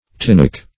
tinnock - definition of tinnock - synonyms, pronunciation, spelling from Free Dictionary Search Result for " tinnock" : The Collaborative International Dictionary of English v.0.48: Tinnock \Tin"nock\, n. (Zool.)